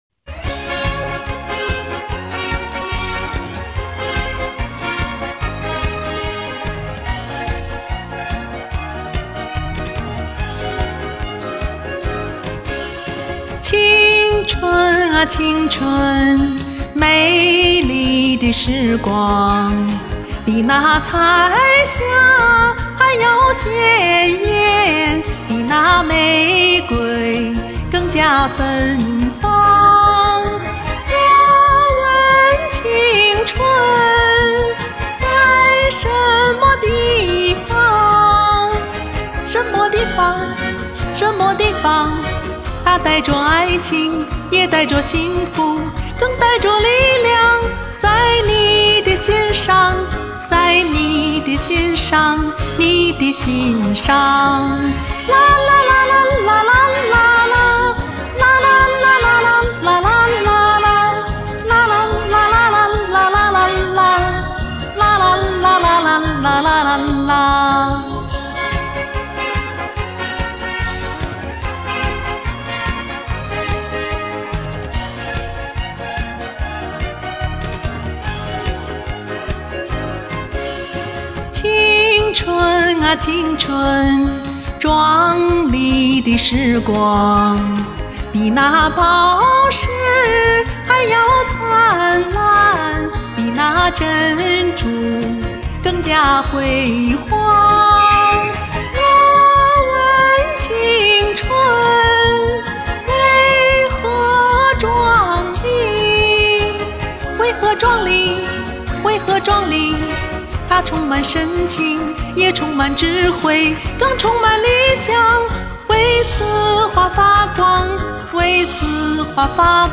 歌曲活泼优美
和你的音色很配，感情细腻，整体把握非常棒，让人百听不厌，算得上精品中的金品😁